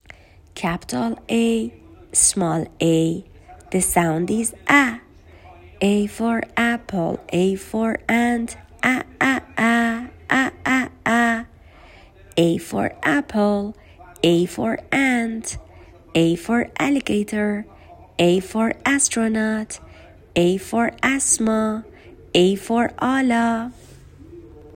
حروفی که بچه ها یاد گرفتند تا اینجا رو در قالب چند ویس ، گذاشتم.
حرف Aa با صداش و لغاتش